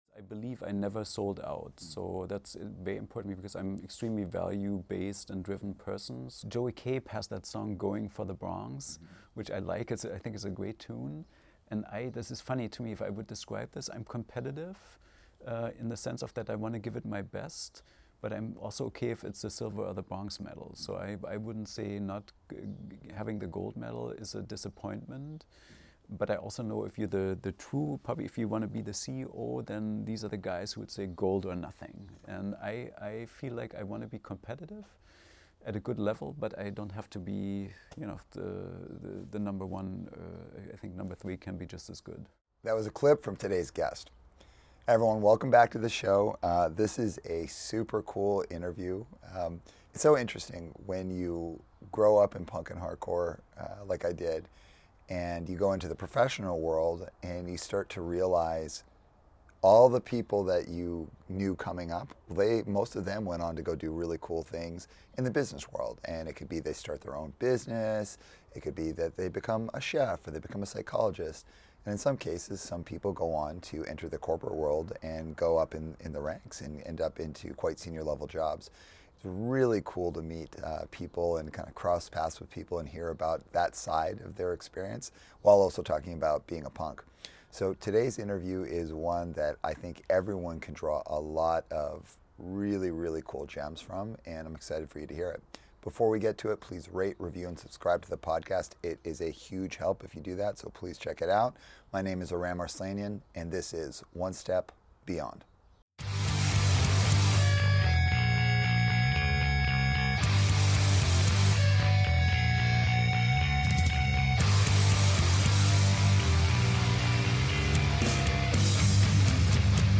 This conversation explores the concept of not opting out to create an impact, and using motivated reasoning to shape our approach to business. It also touches on the importance of fostering an environment where employees feel respected and empowered to act independently.